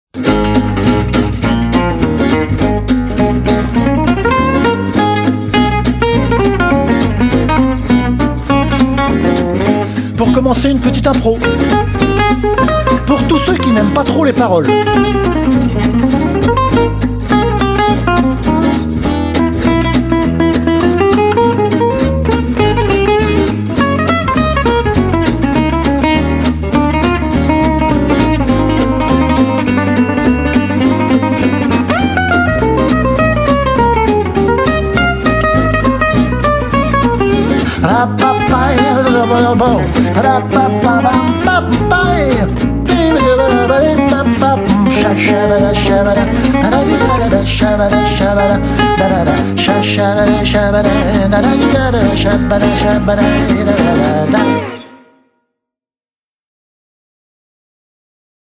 Si vous aimez le swing
les guitares